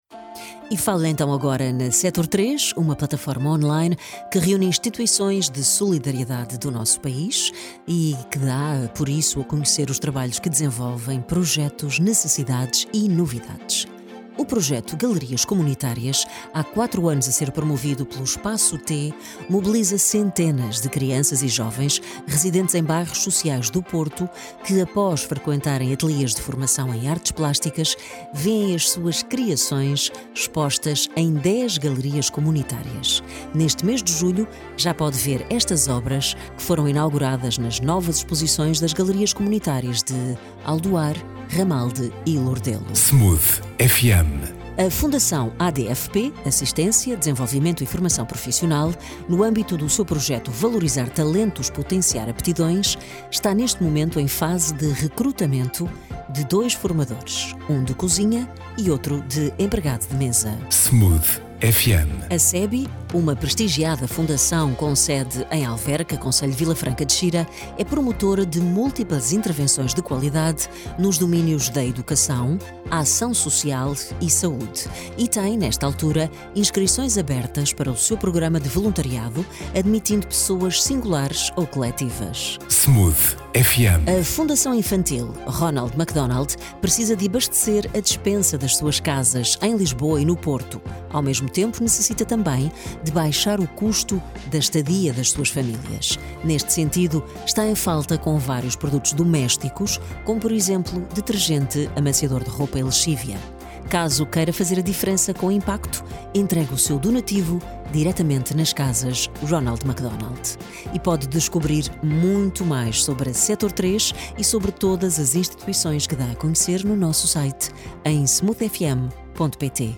16 julho 2025 Flash Smooth FM Espaço T | Fundação ADFP | Fundação CEBI | Fundação Infantil Ronald McDonald partilhar Facebook Twitter Email Apontamento rádio sobre a atividade desenvolvida por entidades-membros do Diretório Sector 3, que vai para o ar todas as quartas-feiras, às 8h, às 13h e às 17h.
Oiça a gravação do spot rádio no ficheiro anexo